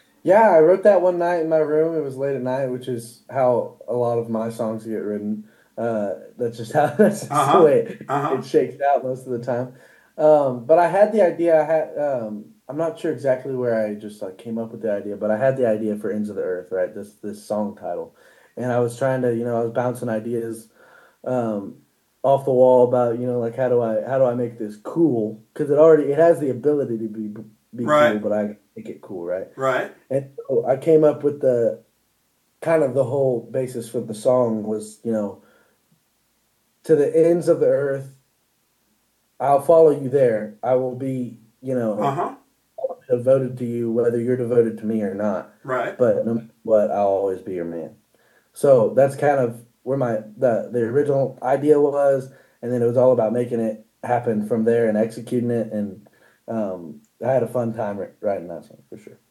Ty Myers Interview - The Select Album.
Interview with young country artist Ty Myers, about his debut album The Select, his hit "Ends of the Earth" and how he started in the music business.
Ty_Myers_interview_excerpt.mp3